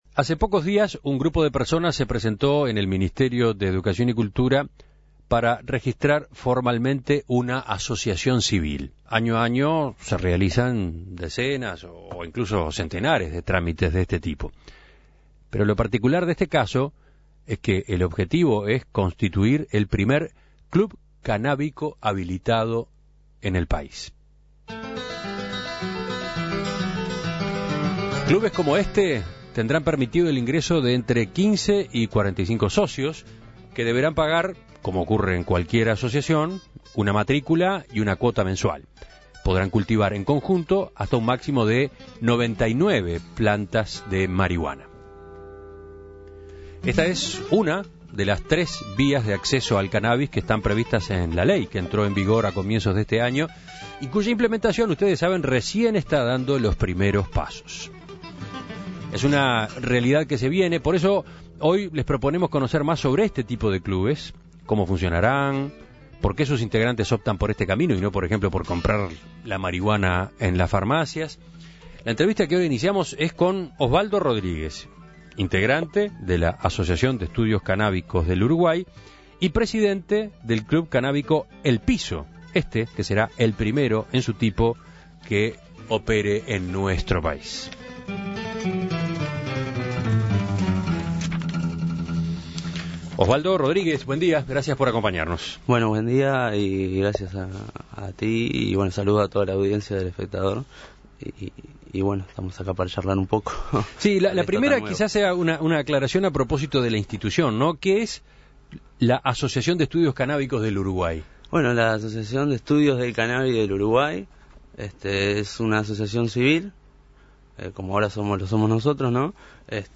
Los clubes de cultivadores y consumidores de cannabis son una de las tres vías de acceso a la marihuana permitidas por la ley que entró en vigor hace poco, por eso días atrás se presentó la solicitud para registrar el primer club cannábico del país. ¿Qué es y cómo funciona un club de estas características? Para conocer cómo se enmarca un club de este tipo en el nuevo marco normativo, En Perspectiva entrevistó